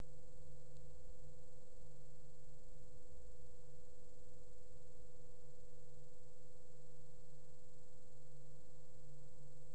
Minimale Umdrehung von 1100 U/min
enermax_warp_120_min.wav